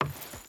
Footsteps / Wood / Wood Chain Walk 3.wav
Wood Chain Walk 3.wav